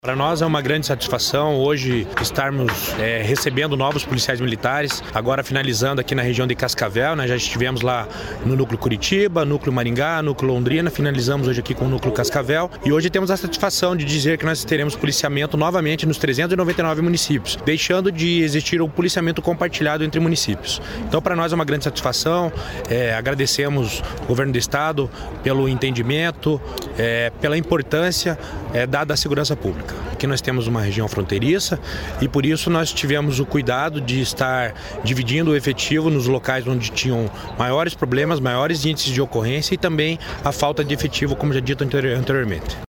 Sonora do comandante-geral da PMPR, Jefferson Silva, sobre a formatura de 319 policiais militares em Cascavel